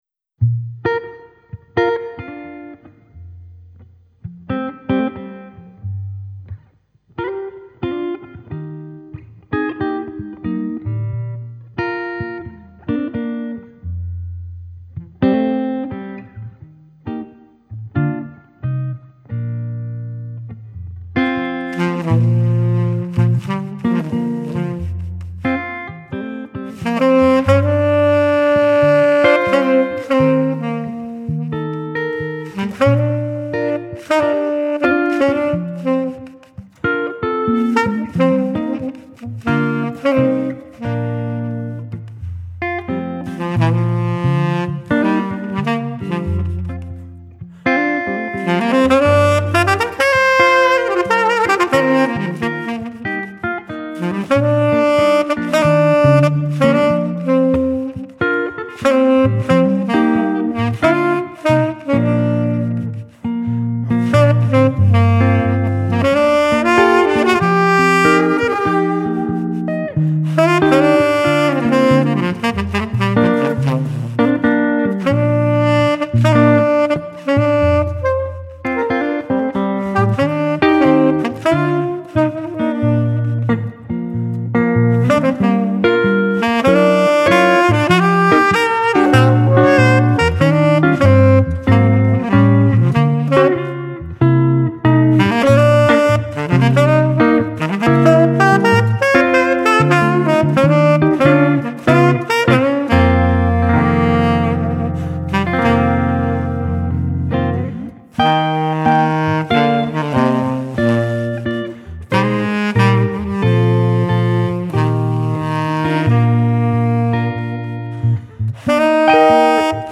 sax / perc
git / loops